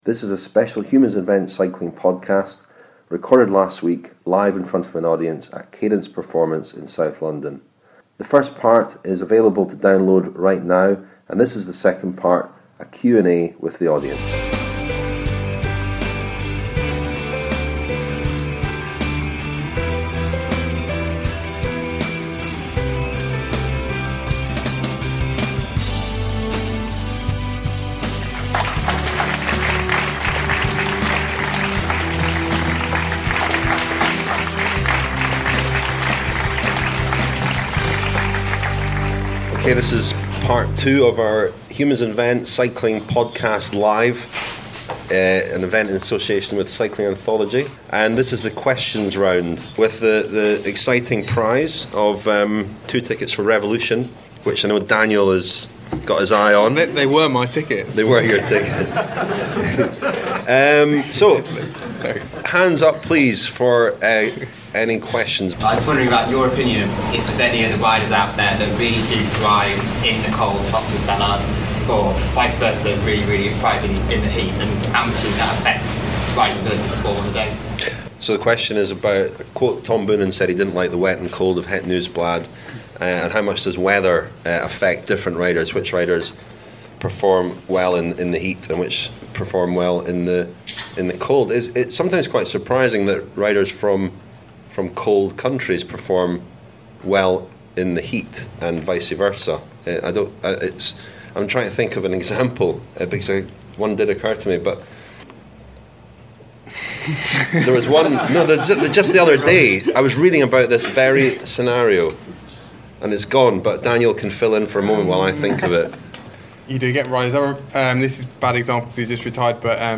LIVE PODCAST Q&A (Part 2)